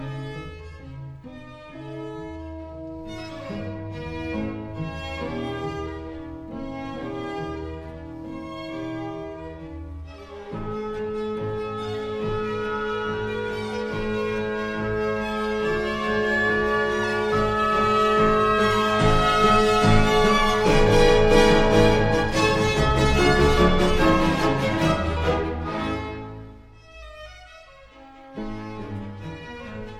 0 => "Musique de chambre"